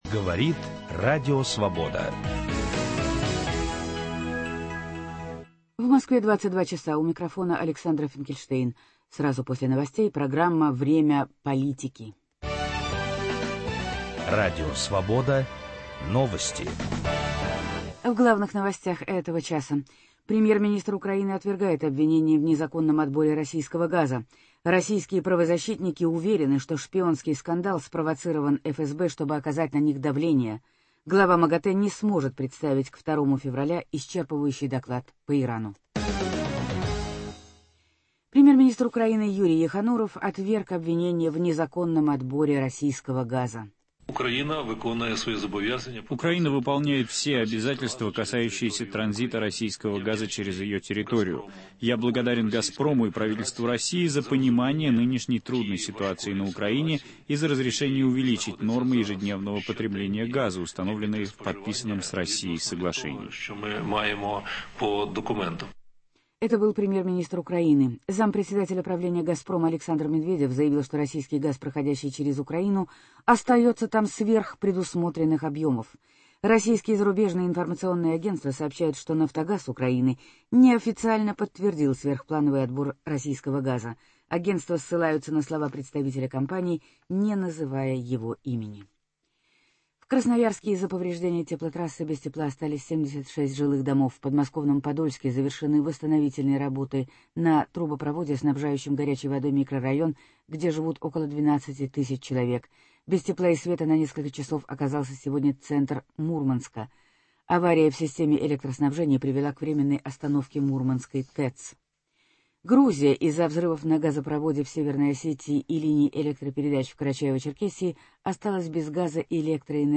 Почему в Курске не допускают к выборам "Народную волю"? Интервью с Александром Руцким.